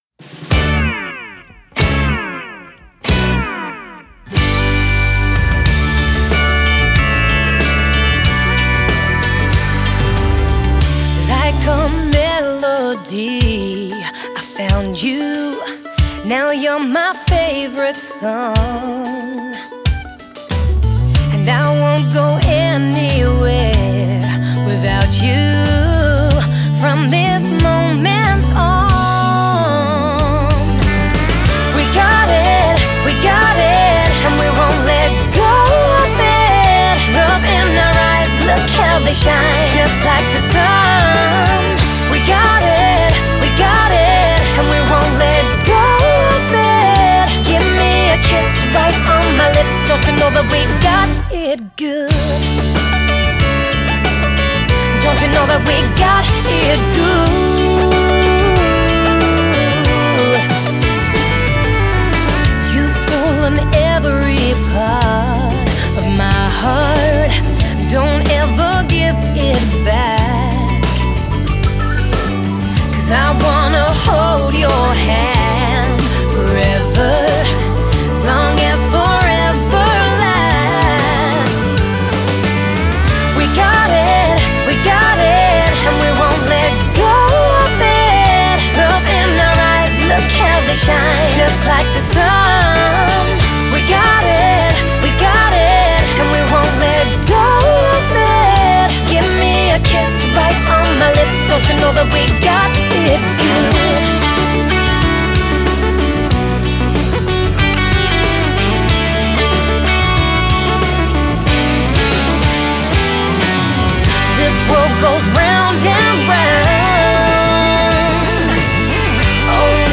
Genre: Country, Folk, Female Vocalist